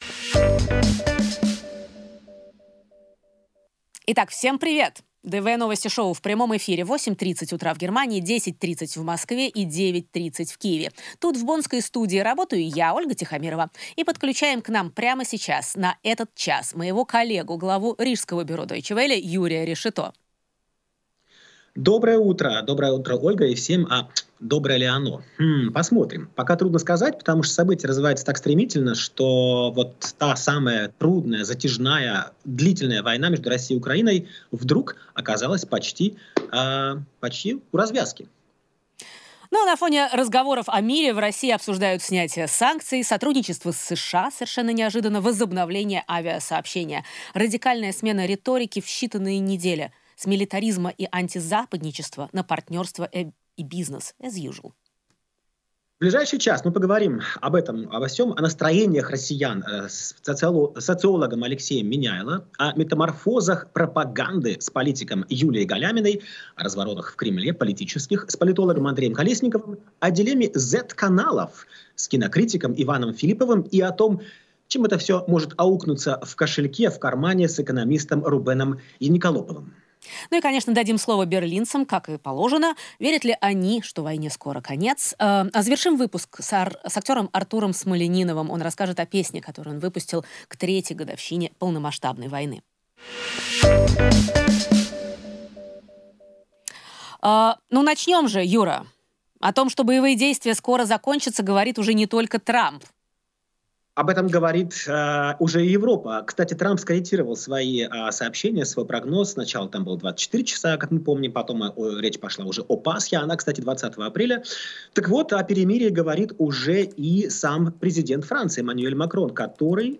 приглашенные ведущие в прямом эфире обсуждают со своими гостями и публикой самые главные события недели.
приглашенные ведущие обсуждают в течение часа со своими гостями в студии в Бонне события недели.